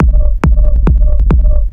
• Techno Hard Minimal Kick.wav
Techno_Hard_Minimal_Kick_LV0.wav